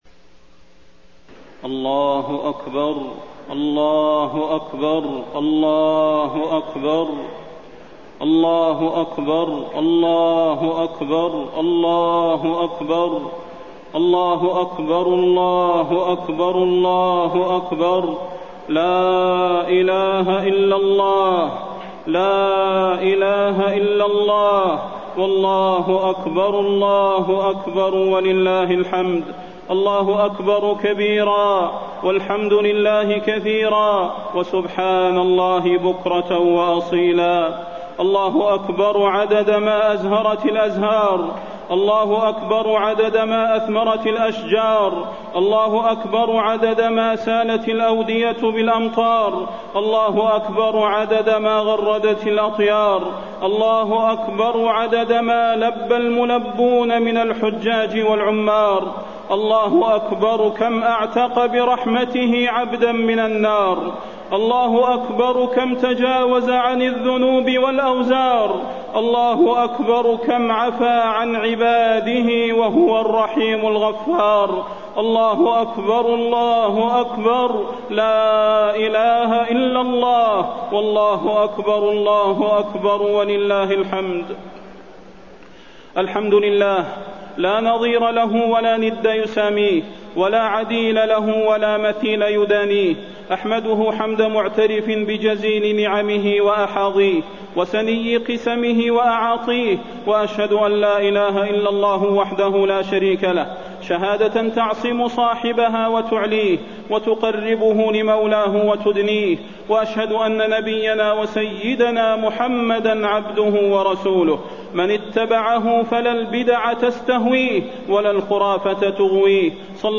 خطبة عيد الأضحى - المدينة - الشيخ صلاح البدير1431
تاريخ النشر ١٠ ذو الحجة ١٤٣١ هـ المكان: المسجد النبوي الشيخ: فضيلة الشيخ د. صلاح بن محمد البدير فضيلة الشيخ د. صلاح بن محمد البدير خطبة عيد الأضحى - المدينة - الشيخ صلاح البدير1431